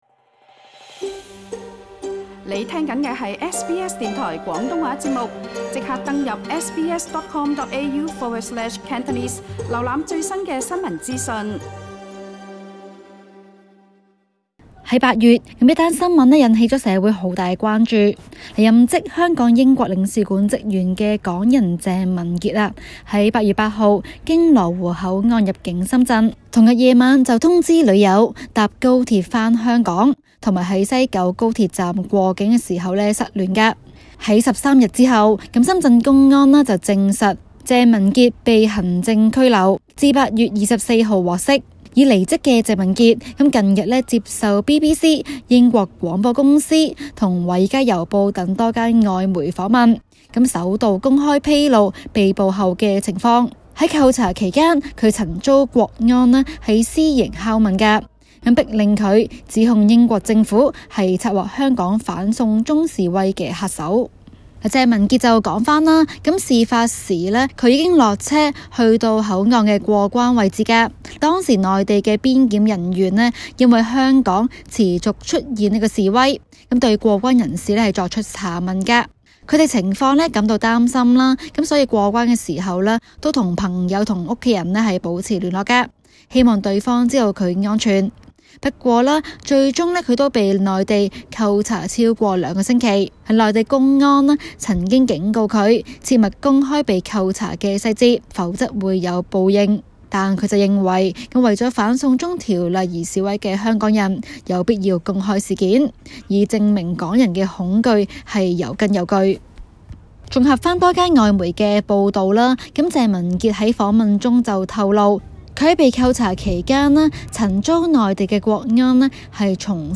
Facebook SBS廣東話節目 View Podcast Series Follow and Subscribe Apple Podcasts YouTube Spotify Download